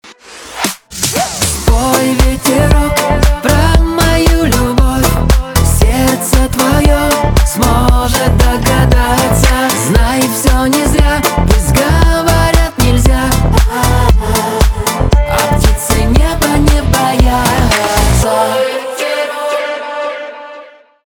Поп